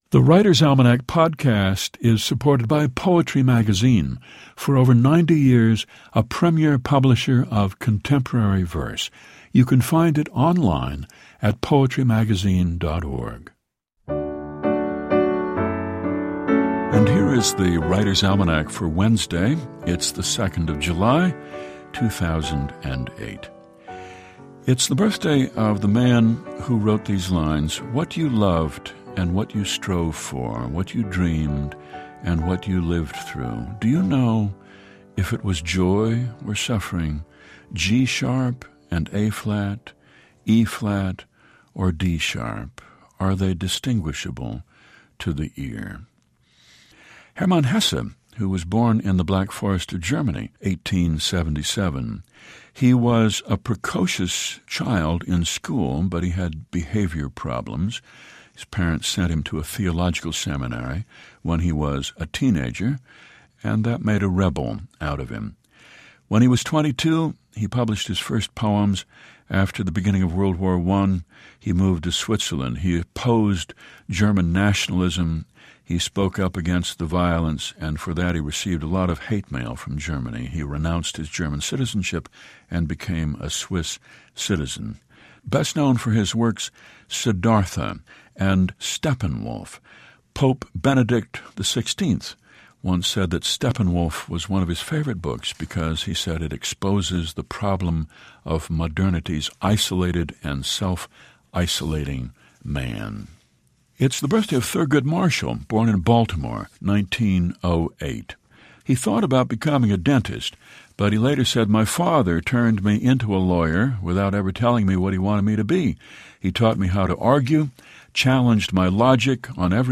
Each day, The Writer's Almanac features Garrison Keillor recounting the highlights of this day in history and reads a short poem or two.